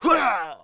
Sound / sound / monster / thief1 / damage_2.wav
damage_2.wav